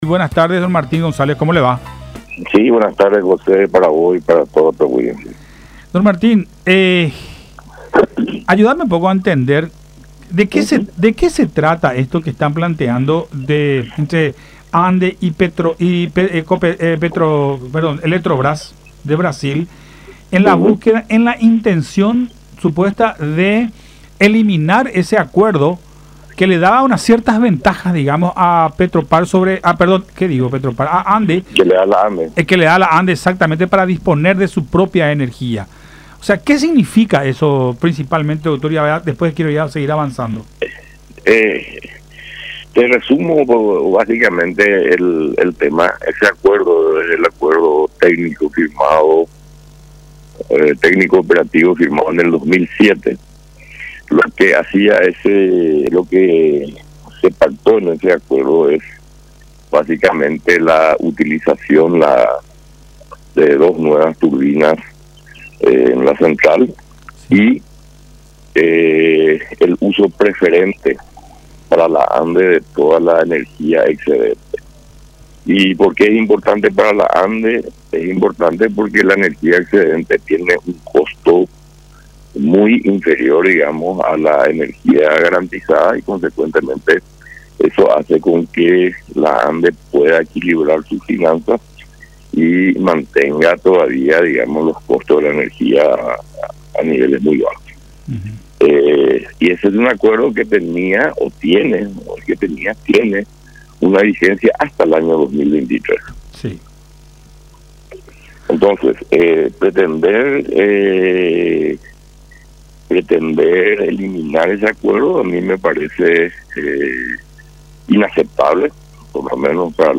“En ese acuerdo técnico – operativo firmado en el año 2007 se pactó la utilización de dos nuevas turbinas en la central y el uso preferente para la ANDE de toda la energía excedente. Esto es muy importante para la ANDE porque la energía excedente tiene un costo muy inferior al de la energía garantizada. Eso hace que la ANDE pueda equilibrar sus finanzas y mantenga los costos de la energía a niveles muy bajos”, explicó González en conversación con Buenas Tardes La Unión, señalando que este pacto tiene vigencia hasta el año 2023.